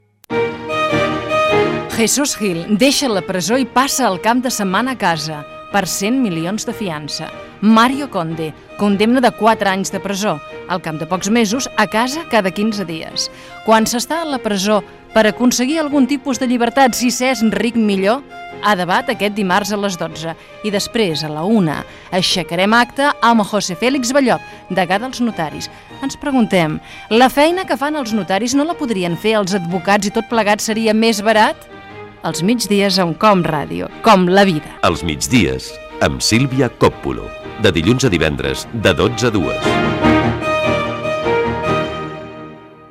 Promoció del programa